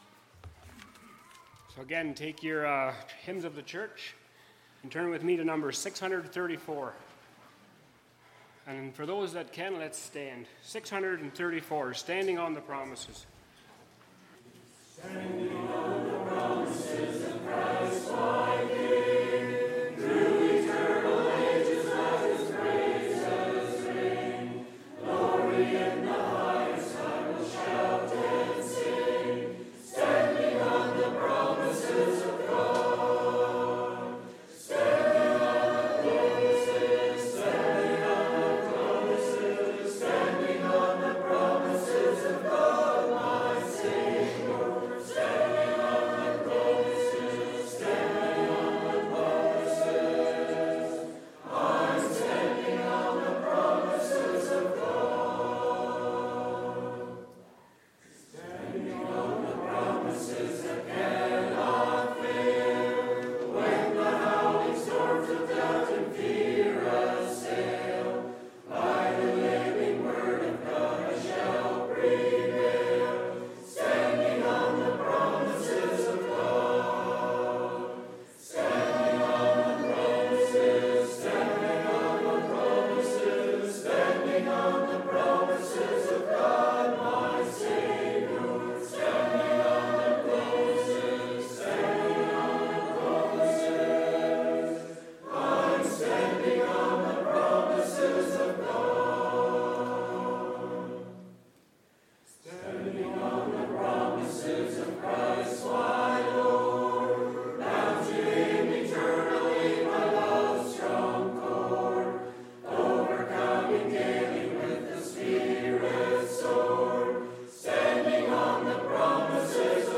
15 Minute Period of Singing
2024 Christian School Administration Institute 2024 (CSAI) 15 Minute Period of Singing Audio 00:00